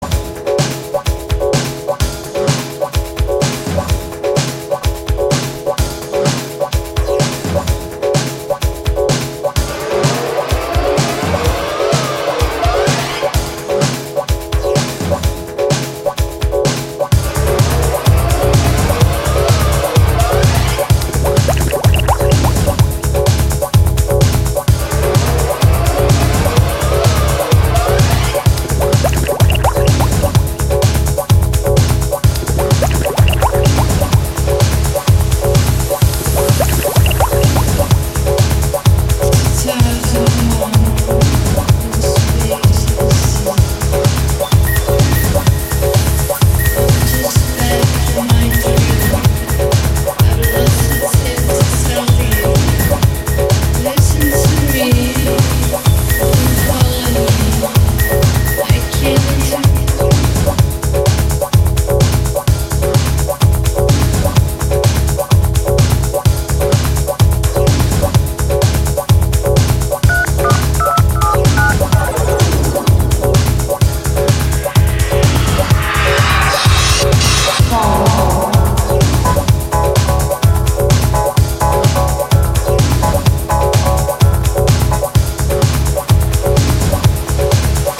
ドライヴ感の強いニューウェイヴ曲